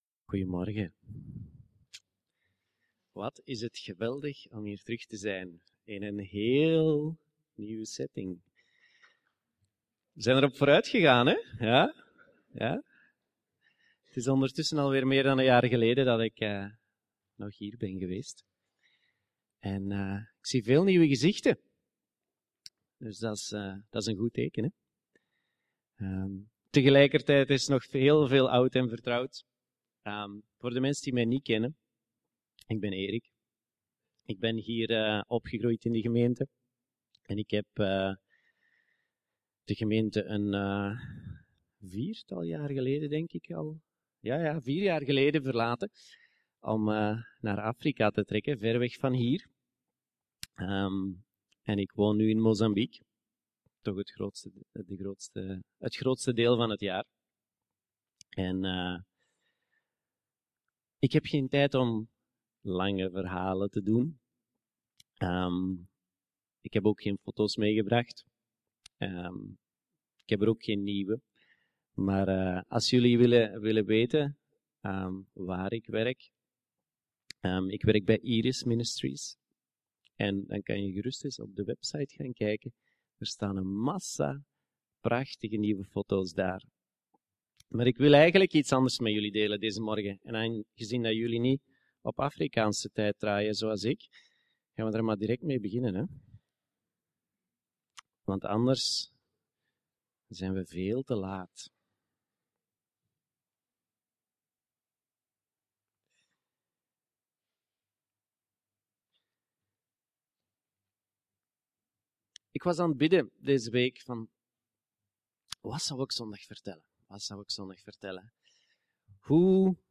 Afrika getuigenis - Levende Hoop